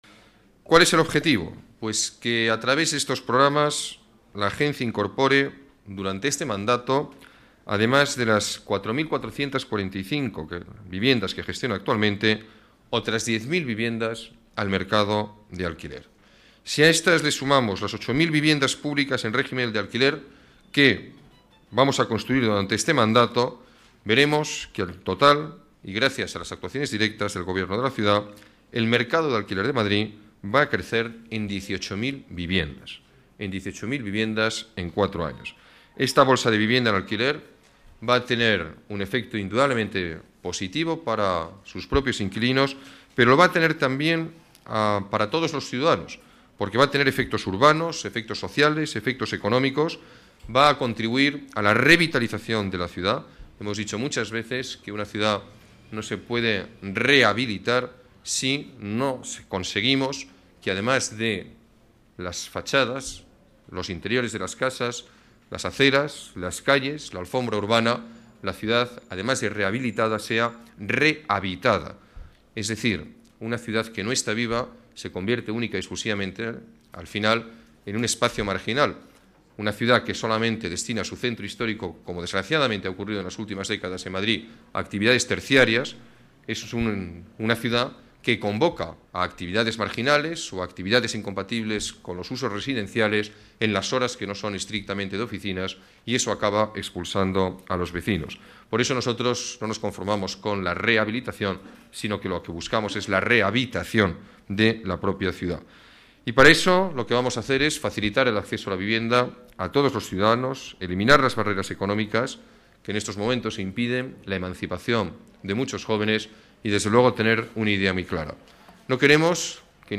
Alberto Ruiz Gallardón reflexiona sobre la necesidad de dar una respuesta a la emancipación de los jóvenes con políticas de vivienda definidas